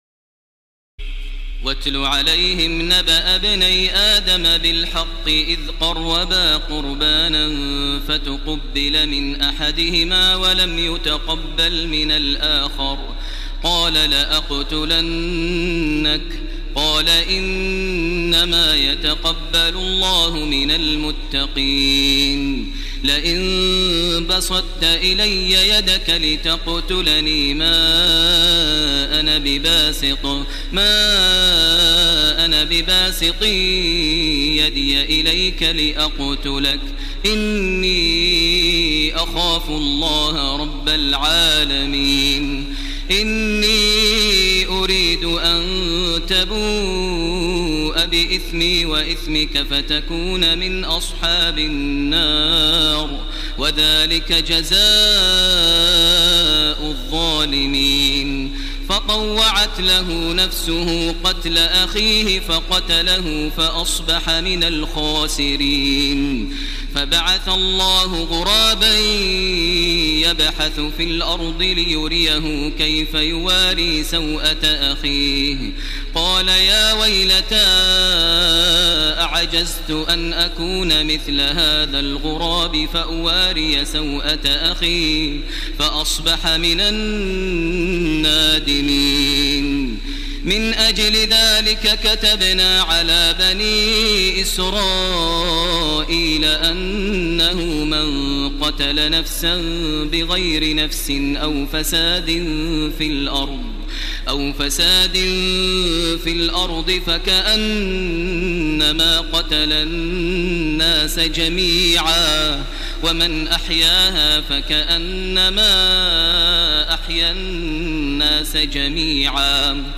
تراويح الليلة السادسة رمضان 1430هـ من سورة المائدة (27-81) Taraweeh 6 st night Ramadan 1430H from Surah AlMa'idah > تراويح الحرم المكي عام 1430 🕋 > التراويح - تلاوات الحرمين